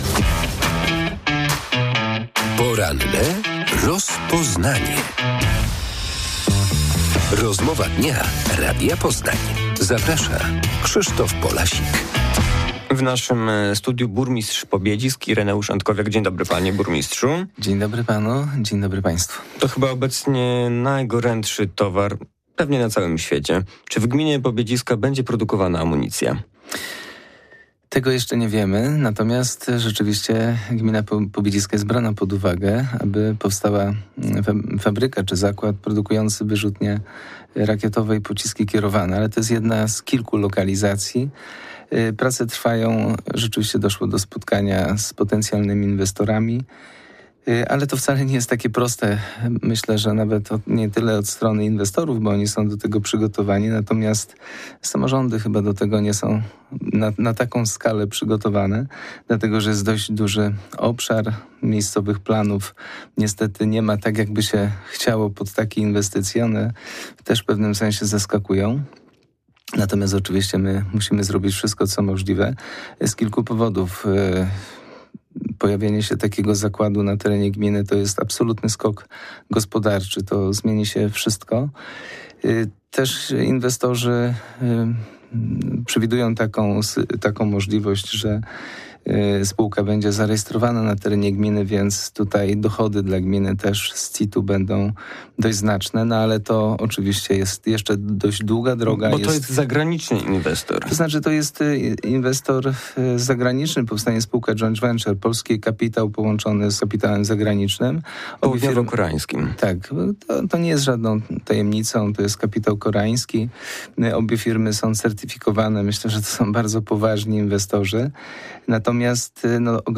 W porannej rozmowie Radia Poznań burmistrz Pobiedzisk Ireneusz Antkowiak odpowiada na pytanie o możliwą budowę fabryki amunicji na terenie gminy oraz protesty mieszkańców Bednar w sprawie głośnej działalności na lotnisku